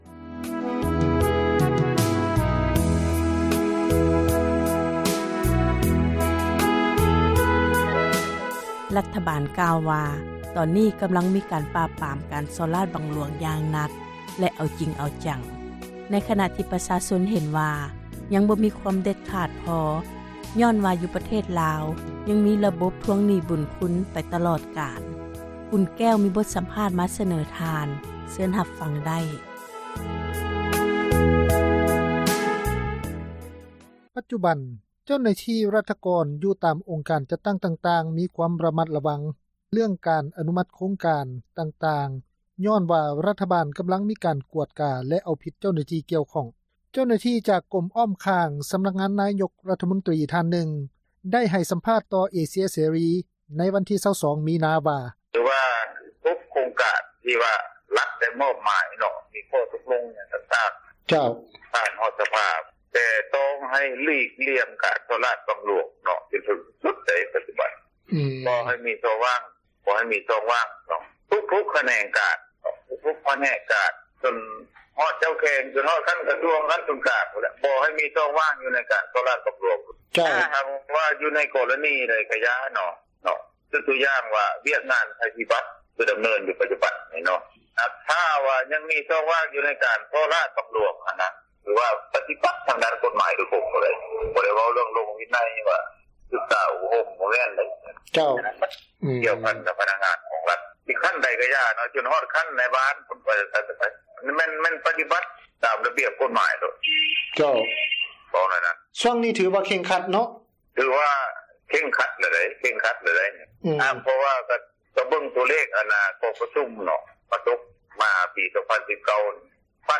(ສຽງສັມພາດ)